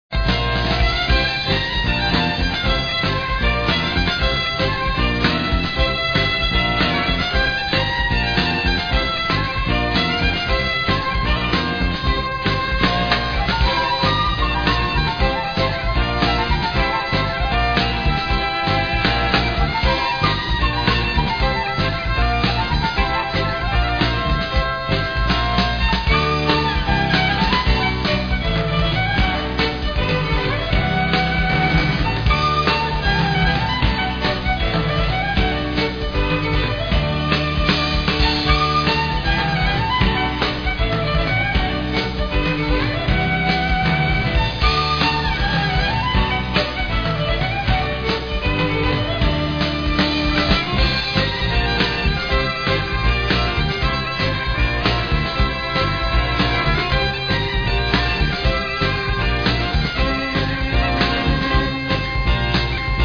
Musica Folk de Cantabria